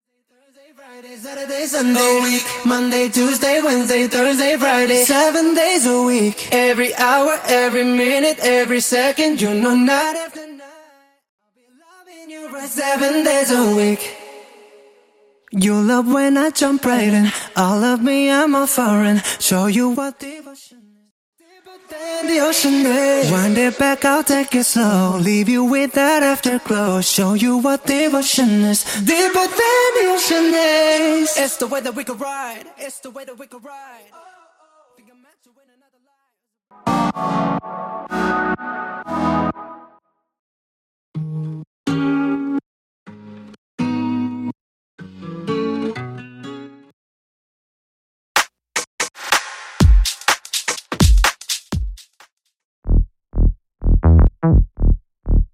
Studio Bassline Stem
Studio Leading Acoustic Guitars Stem
Studio Organic Keys Stem
Studio Percussion & Drums Stem